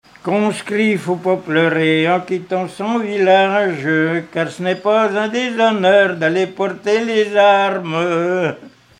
Mémoires et Patrimoines vivants - RaddO est une base de données d'archives iconographiques et sonores.
gestuel : à marcher
Pièce musicale inédite